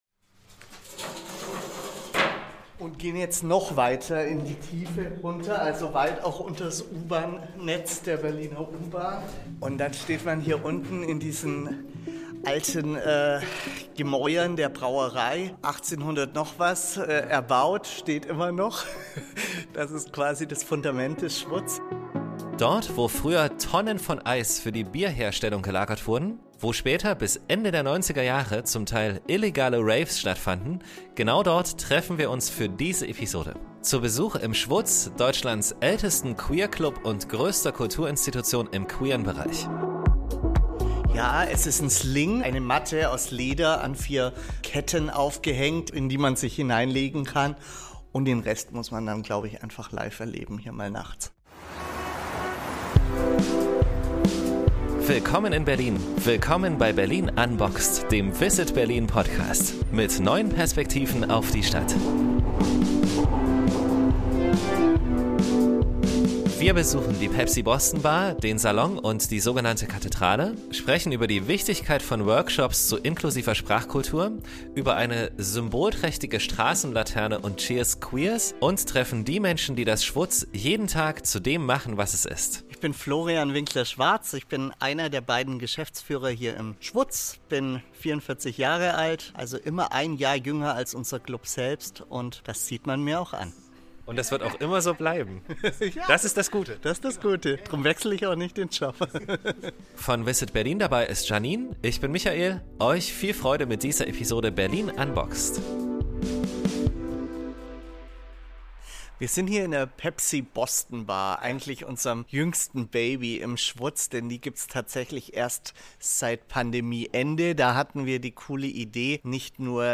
Hier beginnt auch unser Rundgang durch den Club, bei dem wir noch vielen anderen mutigen Menschen aus der Szene begegnen.
Kommt mit in die versteckten Ecken des Clubs und in die alten Eiskeller-Gewölbe tief unter dem SchwuZ. Und natürlich geht es auch um queere Begrifflichkeiten und warum eine inklusive Sprachkultur so wichtig ist – und weshalb sich Berlins pink pillow-Hotels für Offenheit und Toleranz einsetzen und dafür, dass alle Berlin-Gäst:innen so sein dürfen, wie sie sind!